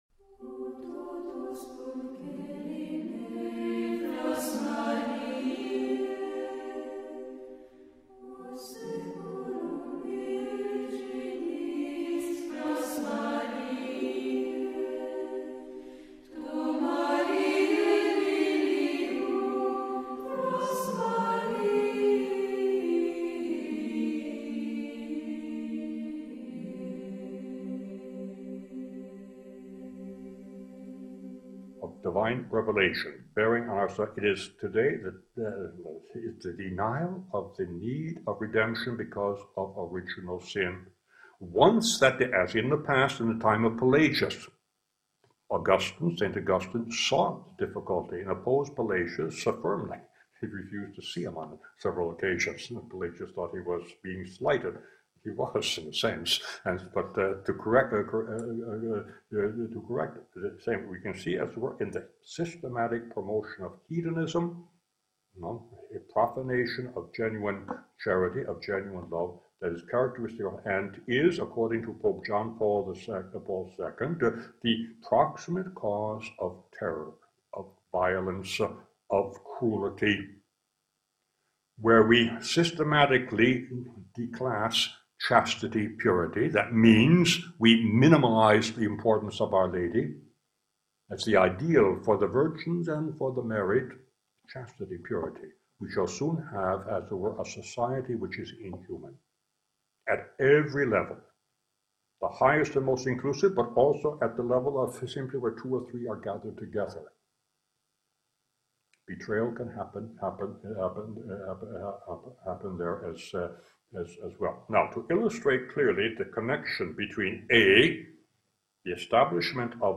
A major Marian conference discussing the impact the proclamation of the Co-redemption Dogma would have on the Church for the triumph of the Immaculate Heart of Mary in the context of approved Marian Apparitions. This conference was held on the anniversary of the last apparition of Our Lady of All Nations on May 31, 1959, the feast of the Visitation in Amsterdam, Netherlands where the apparitions took place along side one of the famous Dutch canals at the historic Victoria Hotel in Park Plaza at the heart of the city in the presence of 100 select guests.